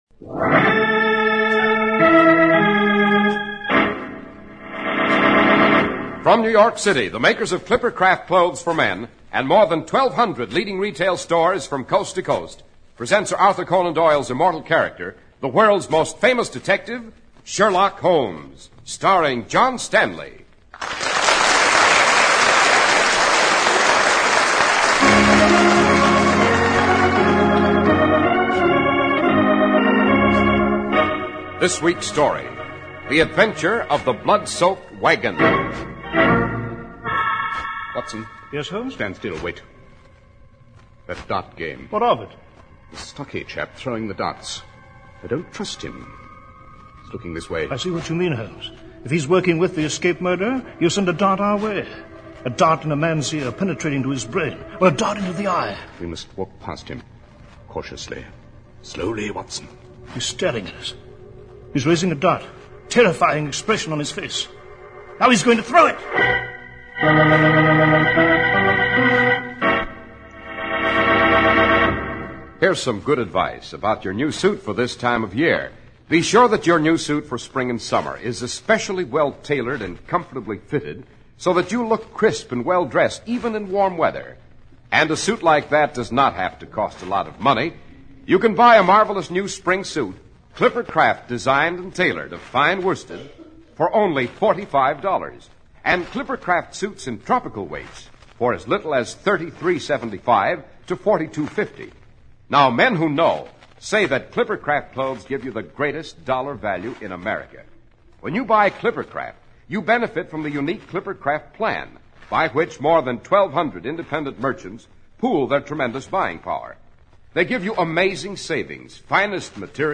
Radio Show Drama with Sherlock Holmes - The Blood Soaked Wagon 1949